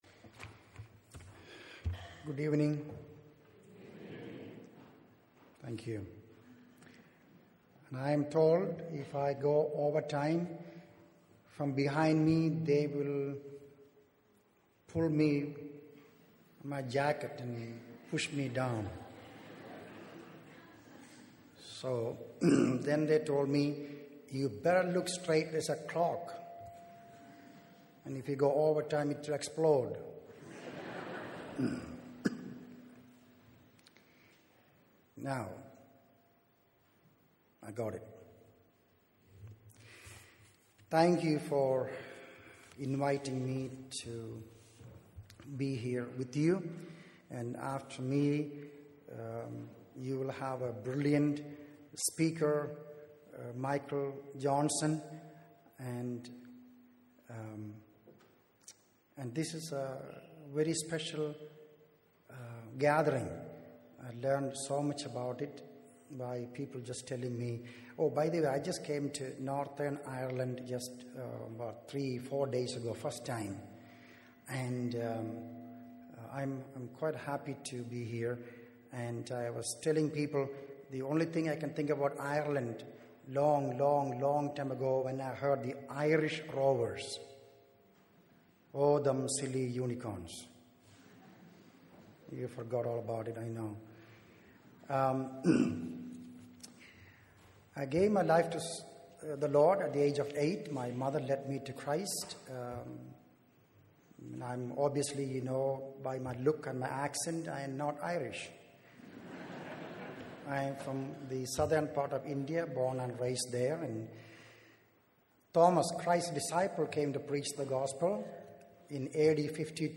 In this sermon, the speaker shares a powerful story of a young man who was sent by Jesus to a dangerous place called Bundi.